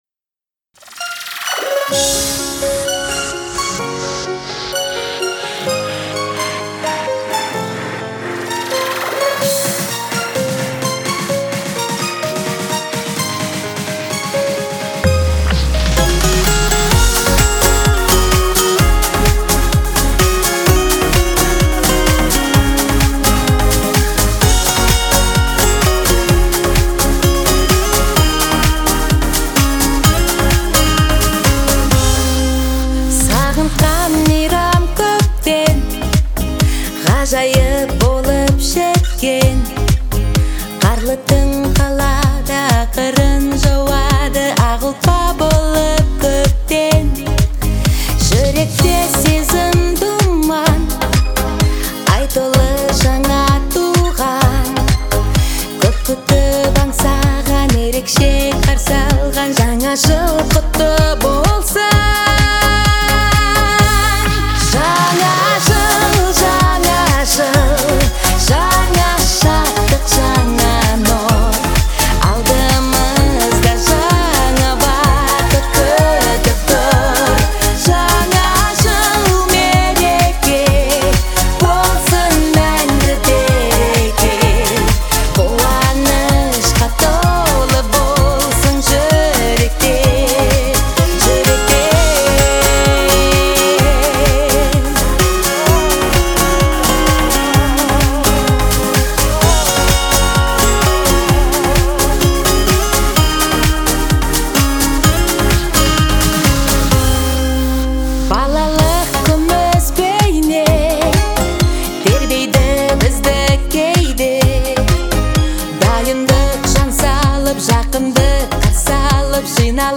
её голос звучит мелодично и эмоционально